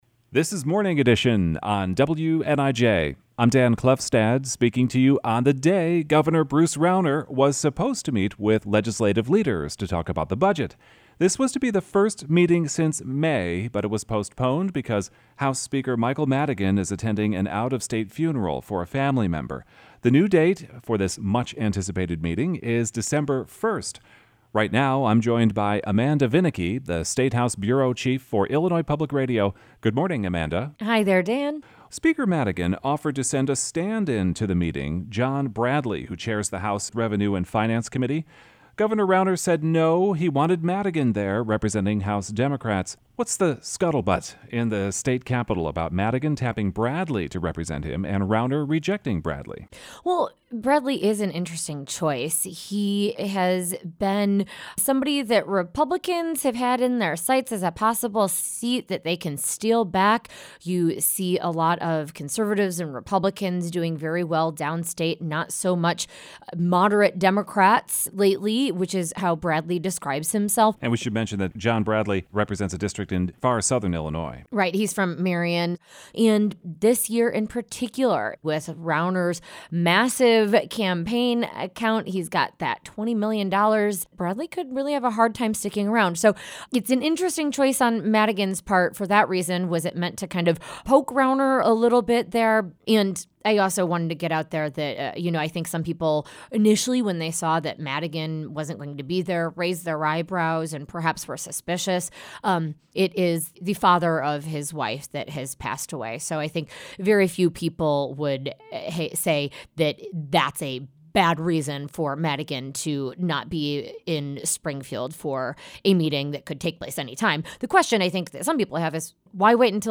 Morning Edition version (Nov. 18, 2015).